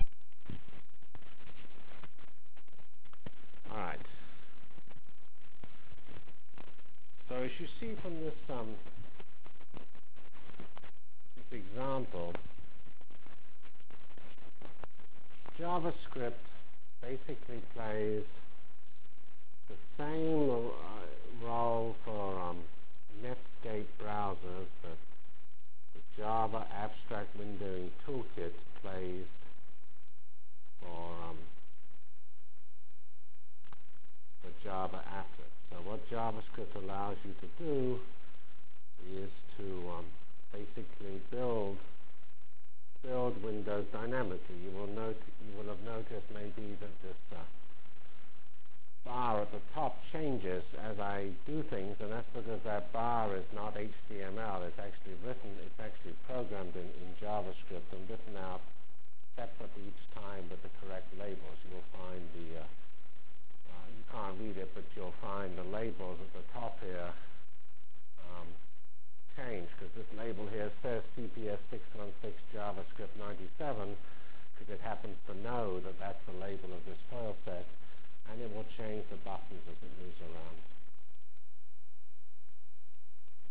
From Feb 10 Delivered Lecture for Course CPS616 -- Introduction to JavaScript CPS616 spring 1997 -- Feb 10 1997. *